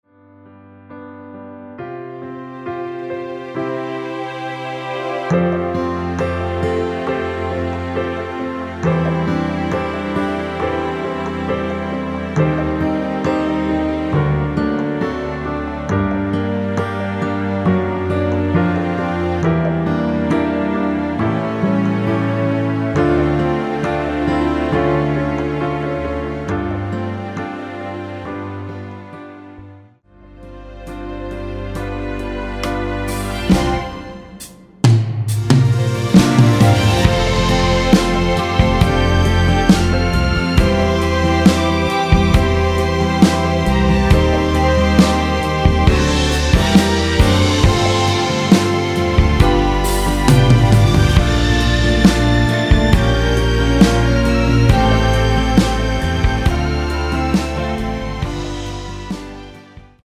전주가 길어서 미리듣기는 중간 부분 30초씩 나눠서 올렸습니다.
원키에서(+1)올린 MR입니다.
중간에 음이 끈어지고 다시 나오는 이유는